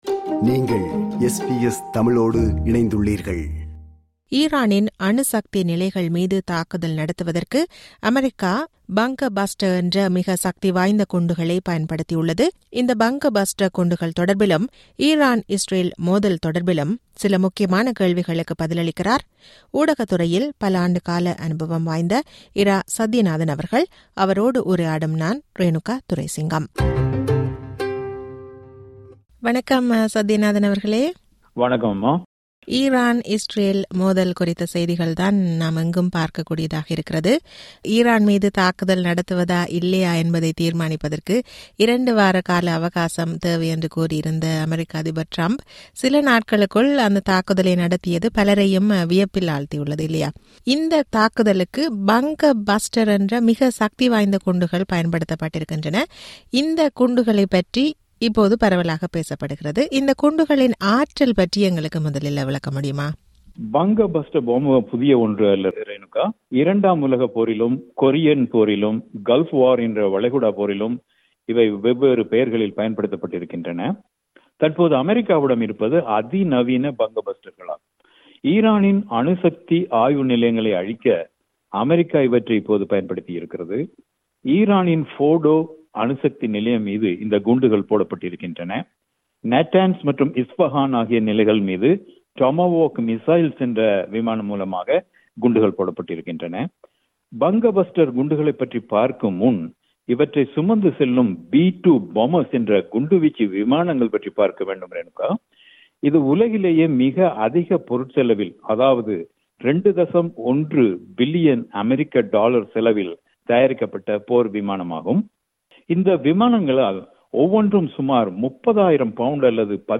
அவரோடு உரையாடுகிறார்